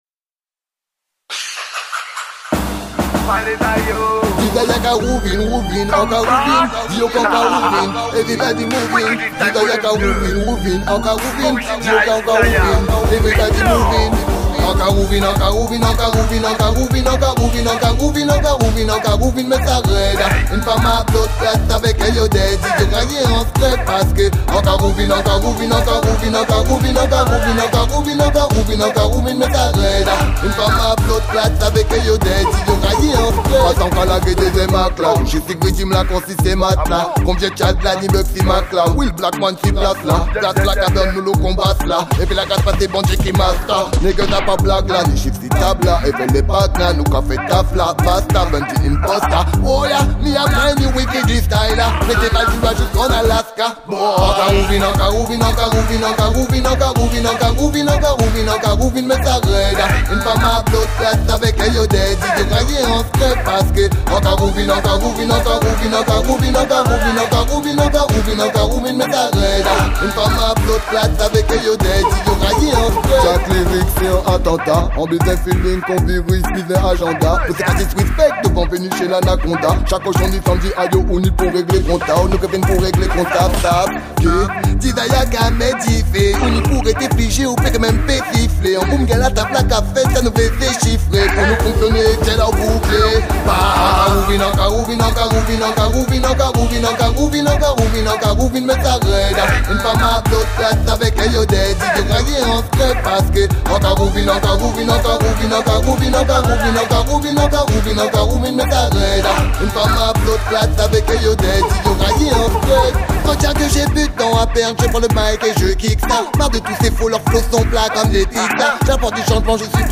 Mixtape Reggae/Dancehall 2015